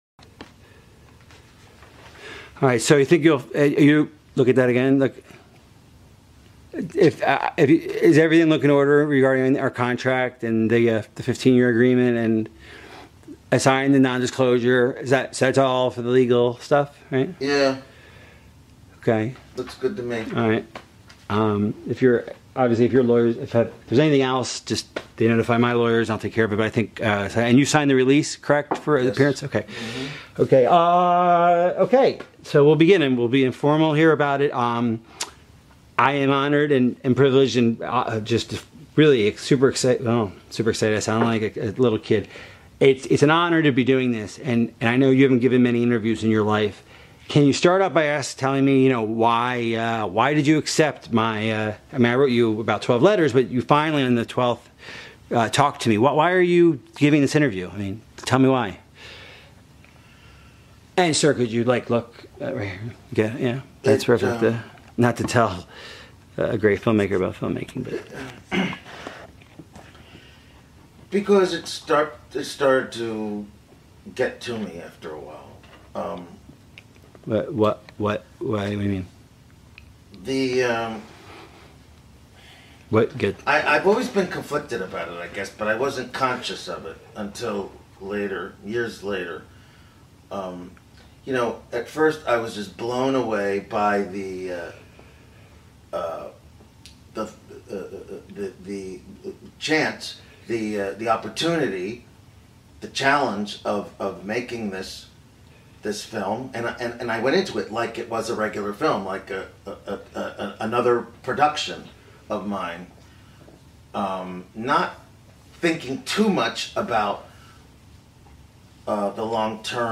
Interview mit Stanley Kubrick am 4. März 1999, drei Tage vor dessen Tod im März 1999. Der Interviewer war gezwungen, ein 88-seitiges NDA zu unterzeichnen, um den Inhalt des Interviews 15 Jahre lang geheim zu halten.